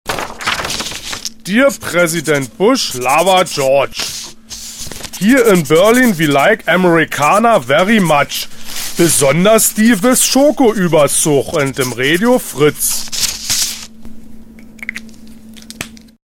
Brief an Bush 2 | Fritz Sound Meme Jingle